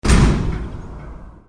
铁门打开.mp3